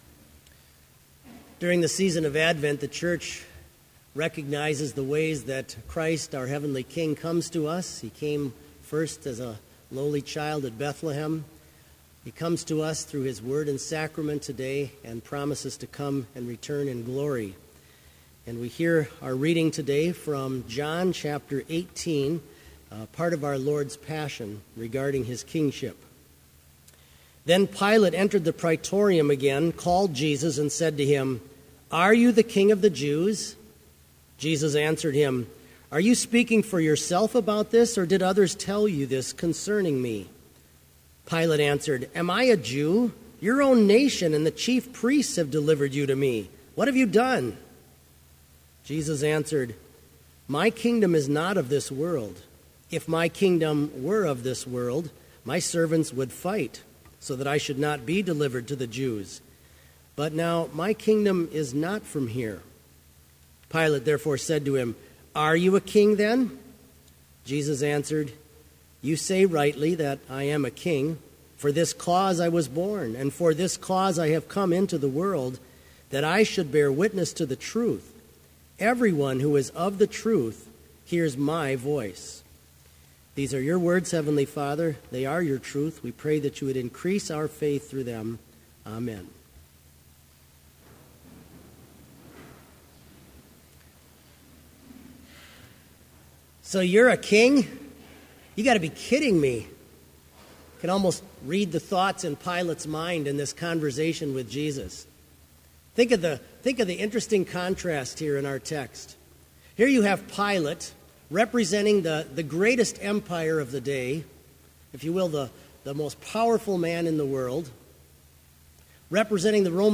Complete Service
This Chapel Service was held in Trinity Chapel at Bethany Lutheran College on Friday, December 4, 2015, at 10 a.m. Page and hymn numbers are from the Evangelical Lutheran Hymnary.